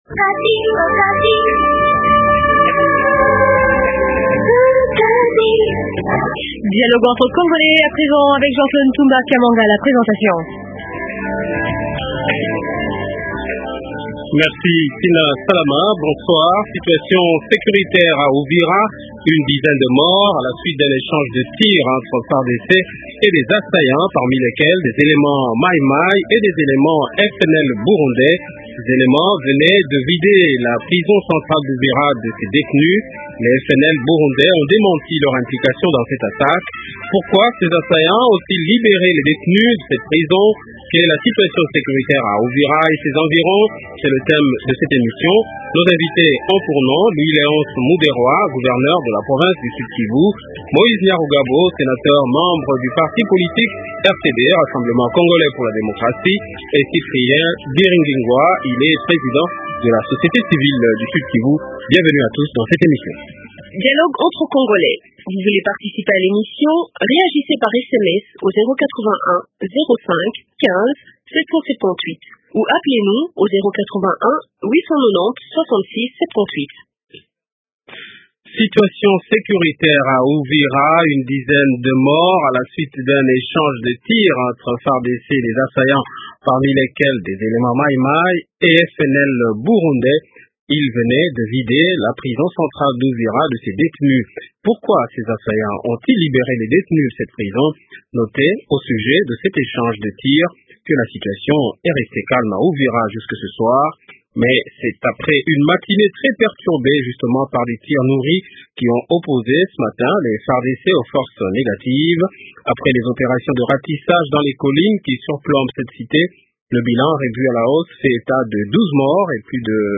Invités - Louis Léonce Muderwa : Gouverneur de la province du Sud Kivu. - Moïse Nyarugabo : Sénateur membre du parti politique Rassemblement congolais pour la démocratie.(RCD)